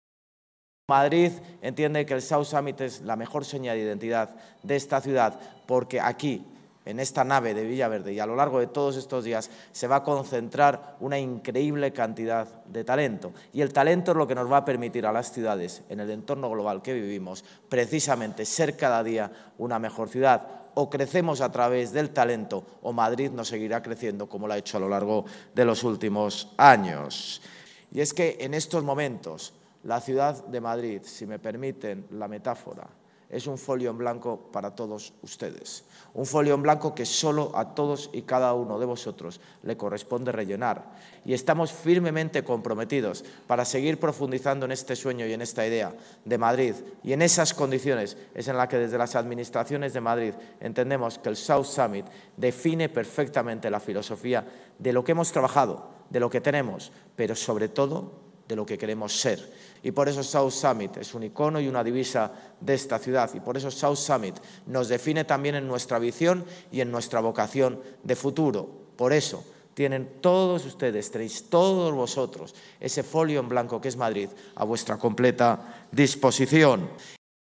Durante la inauguración de la 13ª edición de South Summit, el mayor evento emprendedor y de innovación del sur de Europa, que se celebra en La Nave
Nueva ventana:El alcalde de Madrid, José Luis Martínez-Almeida